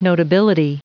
Prononciation du mot notability en anglais (fichier audio)
Prononciation du mot : notability
notability.wav